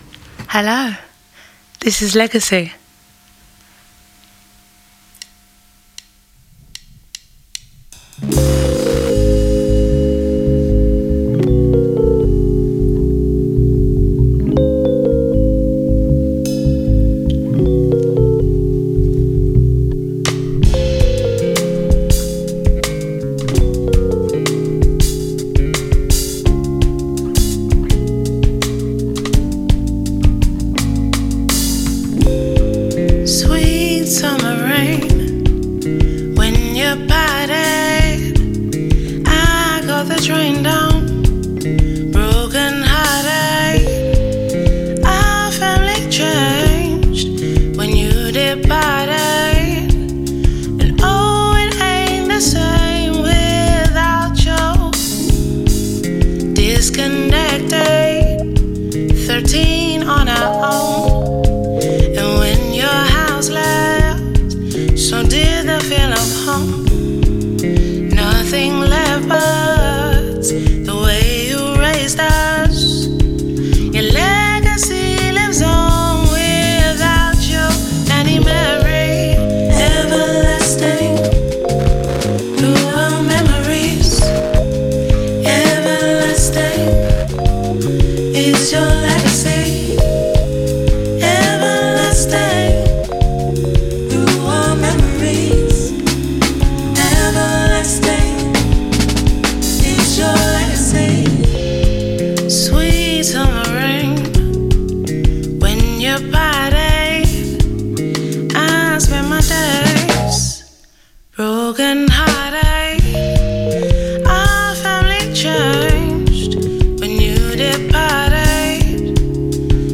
Recorded yesterday at the BBC’s Maida Vale Studios
Soul